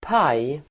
Ladda ner uttalet
Uttal: [paj:]